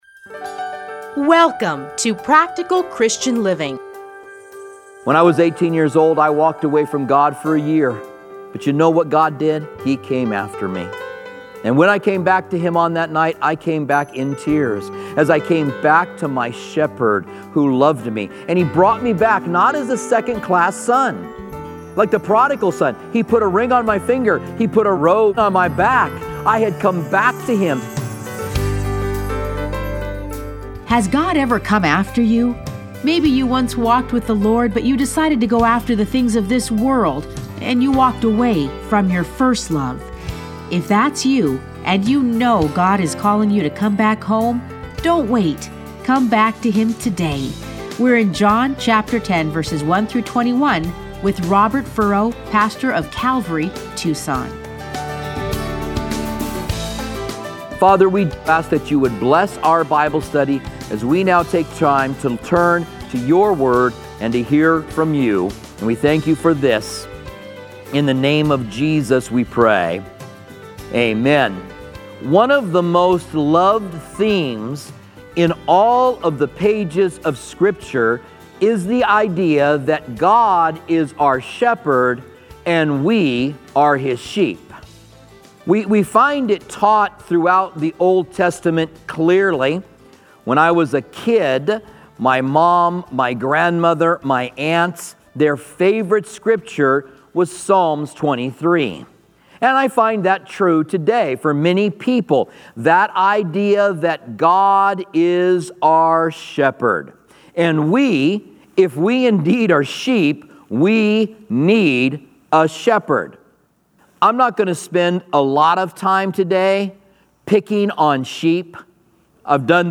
Listen to a teaching from John 10:1-21.